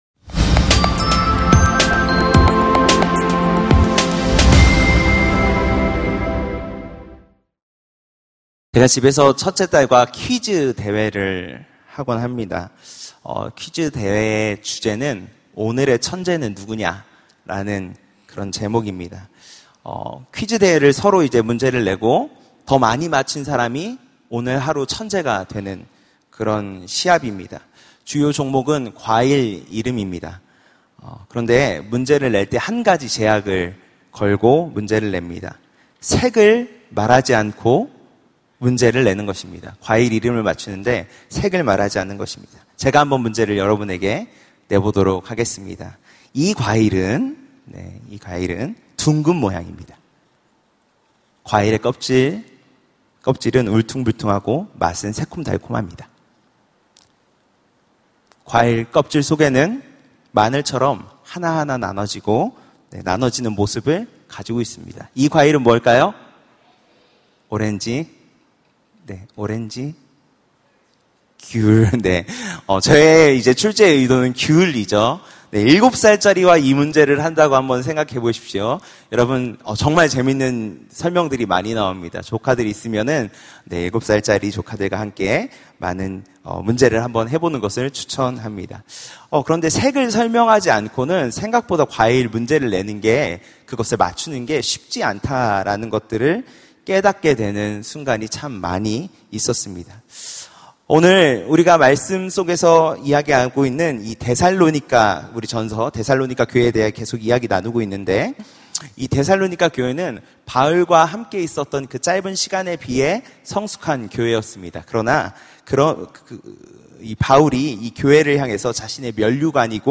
설교 : 파워웬즈데이